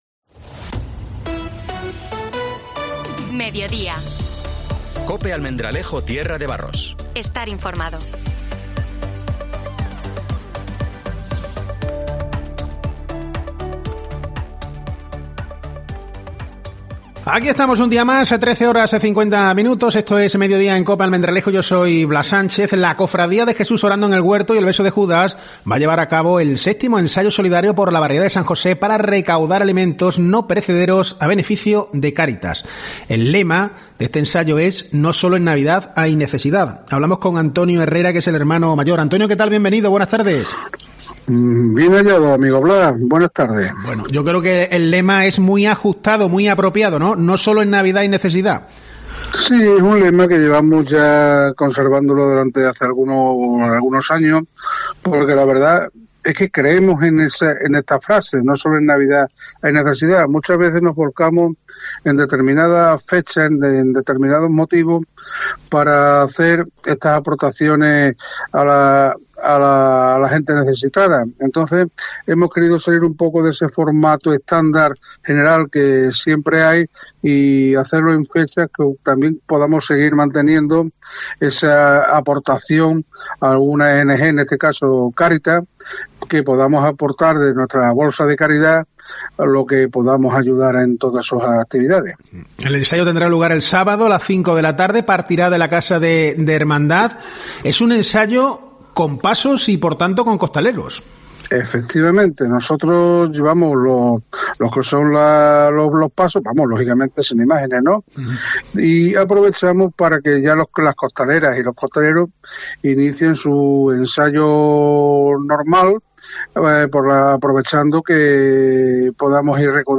hemos entrevistado